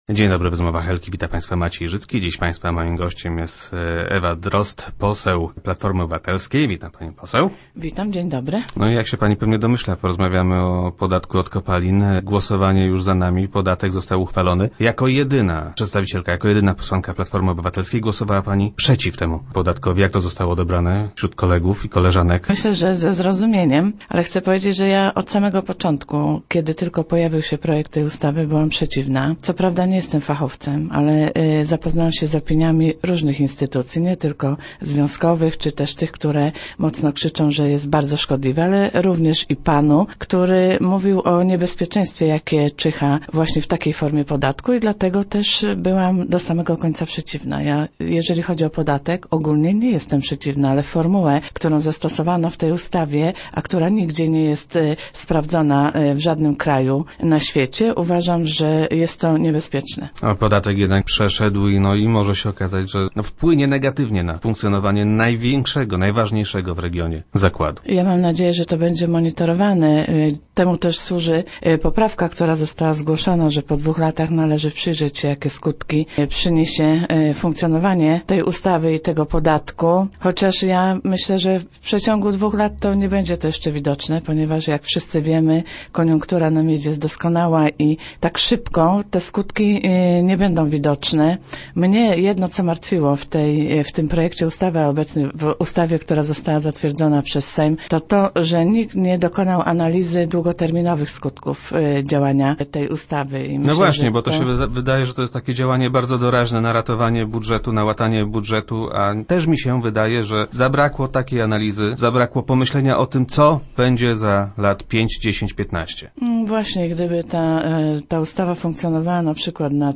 Parlamentarzystka, która złamała klubową dyscyplinę, była gościem wtorkowych Rozmów Elki.